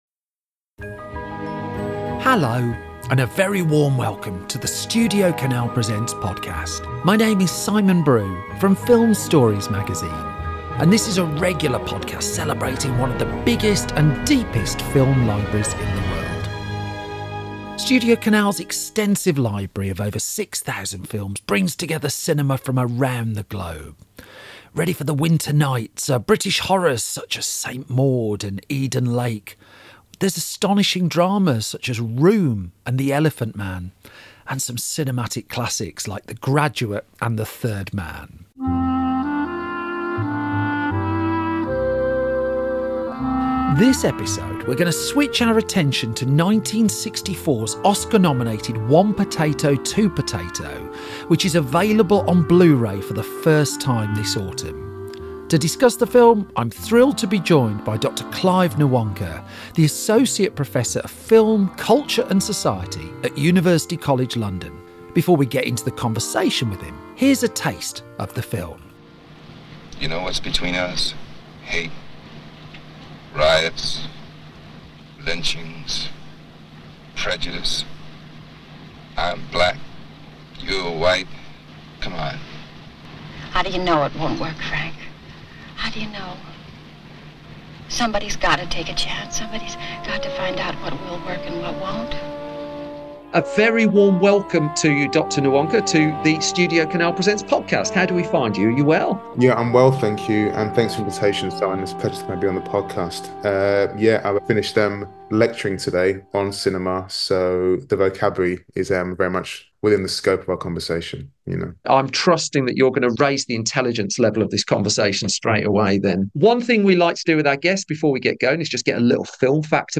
The pair chat about the film, and also bring the similarly underappreciated Pool Of London into the conversation.